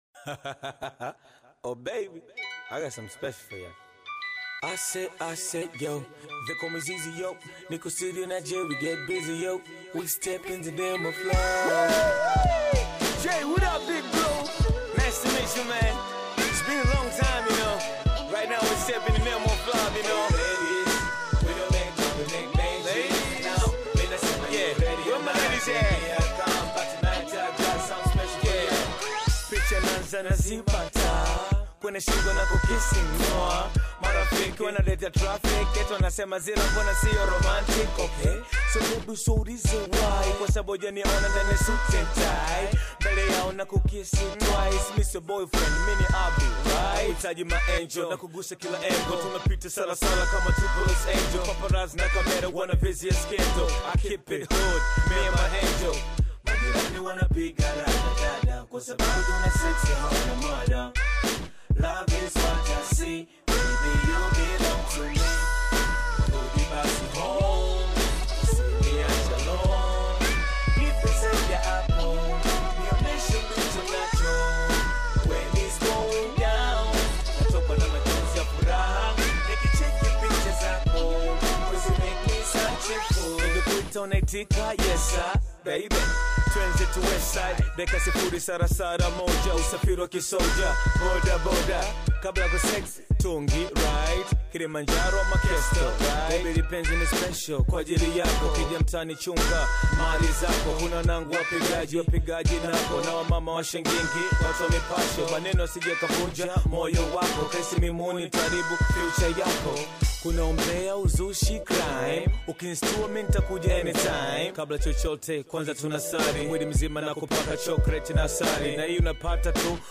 classic Tanzanian hip-hop/Bongo Flava track